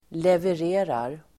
Uttal: [lever'e:rar]